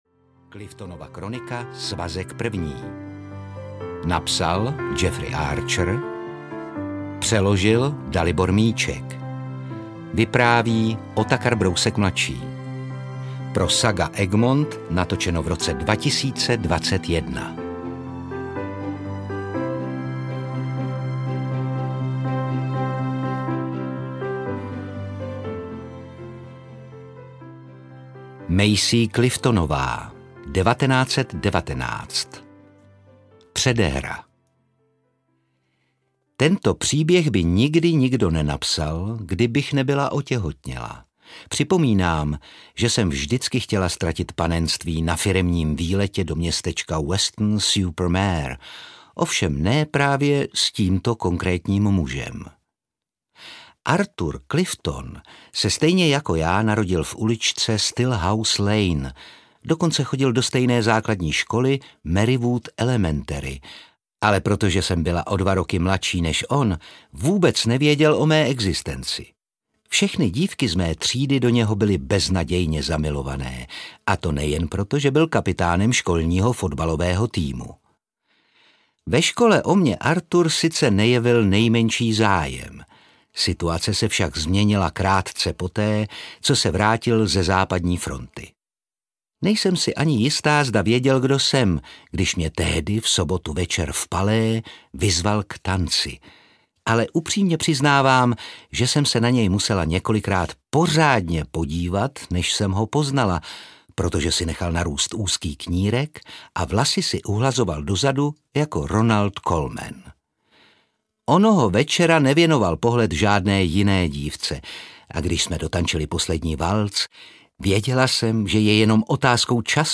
Jen čas ukáže audiokniha
Ukázka z knihy
• InterpretOtakar Brousek ml.